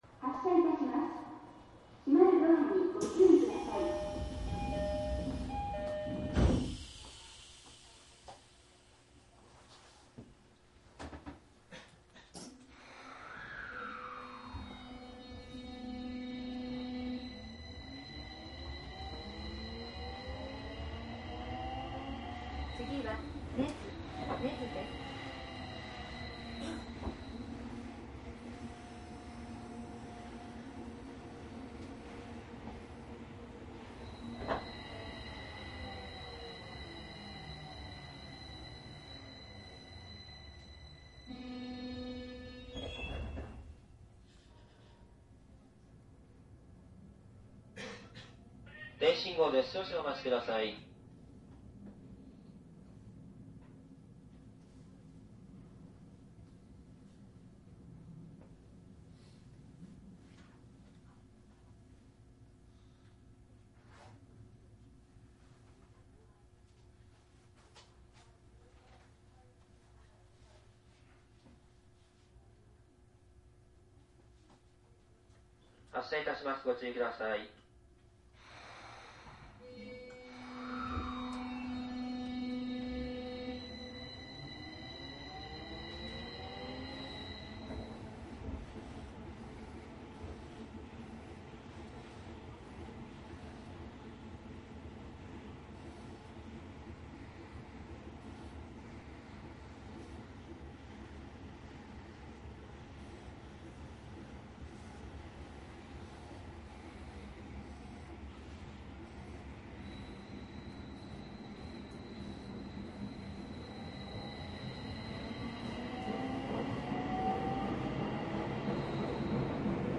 東京メトロ16000系 代々木上原方面 走行音CD
千代田線の16000系を代々木上原方面で録音しました。録音区間は松戸→代々木上原です。線路内人立ち入りの影響でダイヤがかなり乱れて駅間停車が一部あります。
いずれもマイクECM959です。MZRH1の通常SPモードで録音。